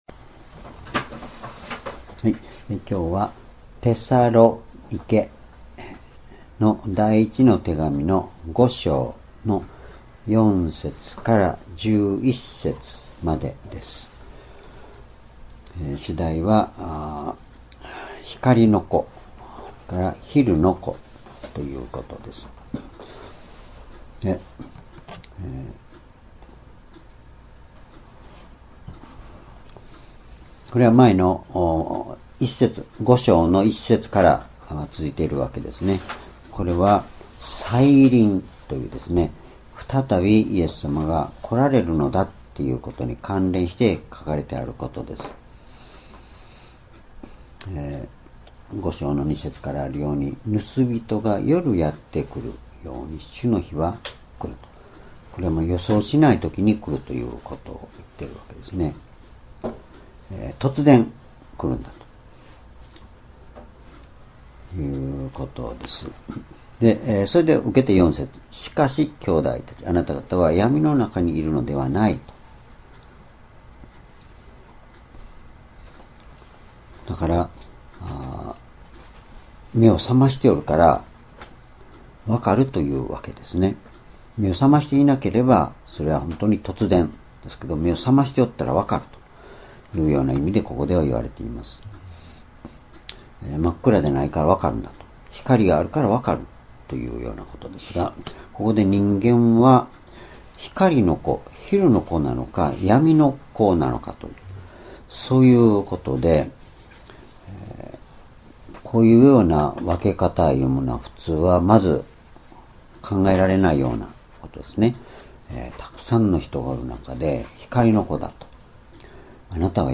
主日礼拝日時 2019年2月26日 移動夕拝 聖書講話箇所 「光の子、昼の子」 テサロニケの信徒への手紙一 5章4節～11節 ※視聴できない場合は をクリックしてください。